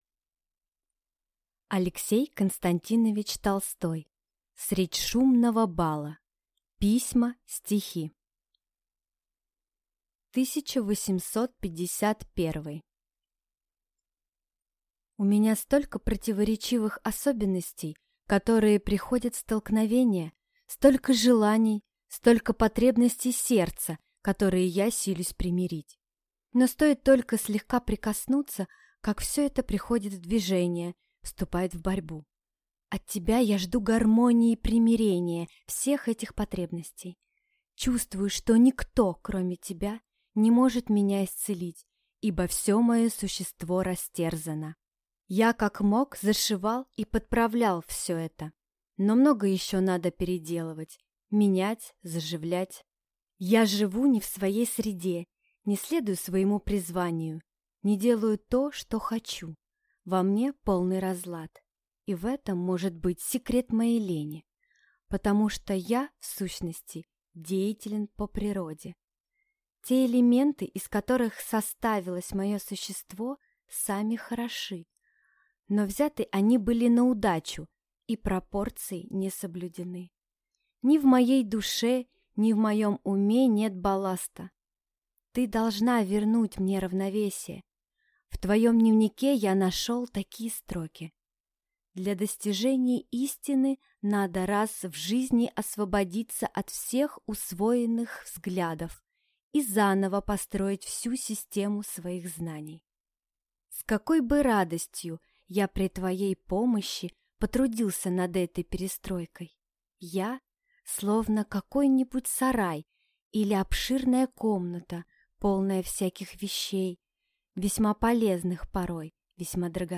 Аудиокнига Средь шумного бала (Письма. Стихи) | Библиотека аудиокниг